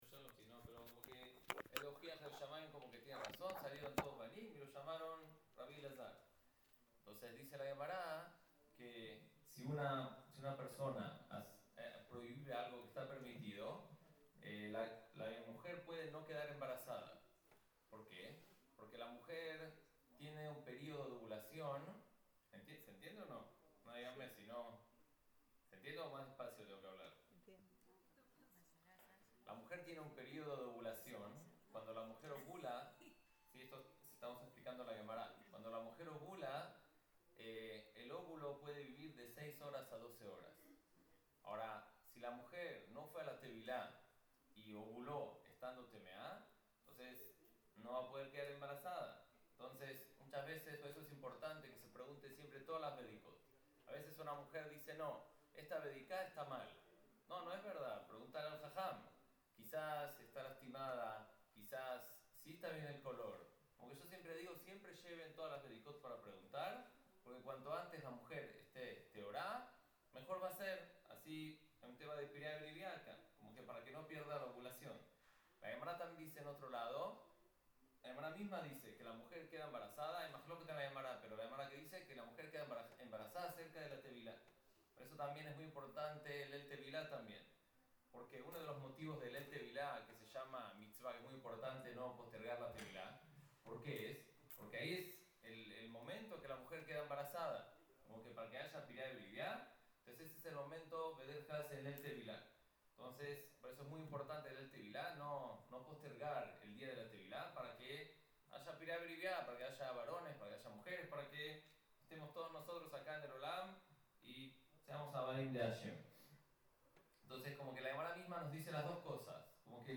LEMALA (shiur não gravado desde o começo) Variados Categoria: Halacha - Lei Judaica